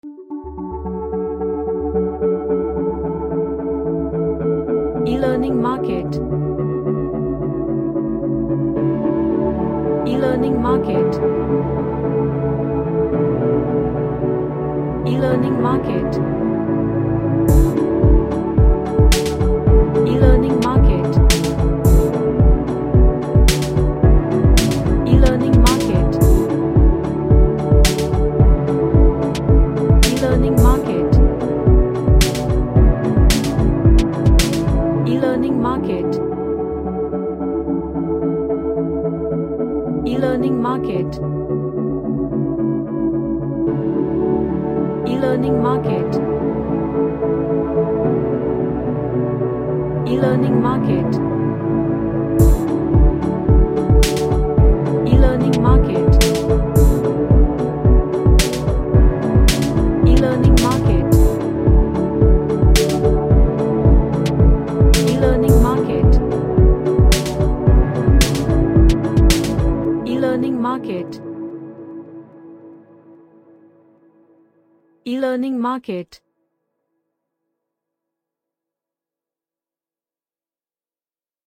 A nice ambient track with relaxing vibe to it.
Happy